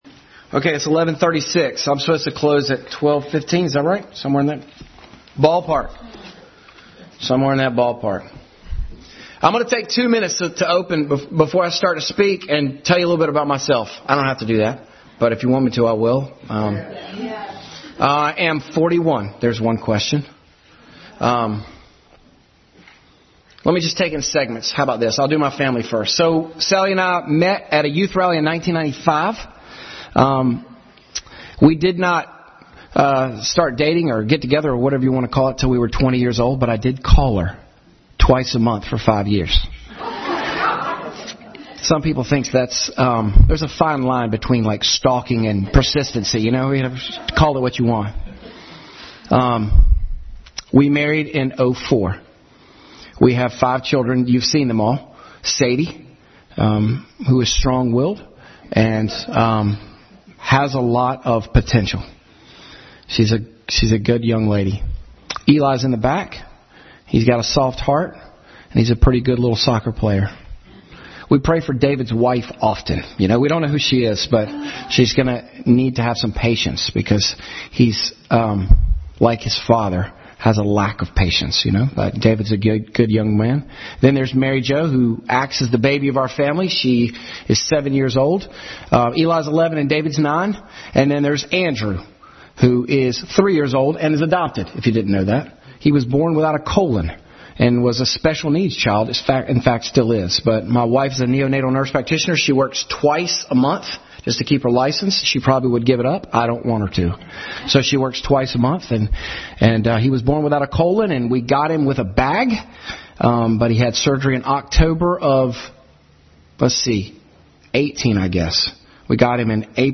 1 Samuel 17:2-58 Passage: 1 Samuel 17:2-58, Judges 13:5 Service Type: Family Bible Hour Family Bible Hour Message.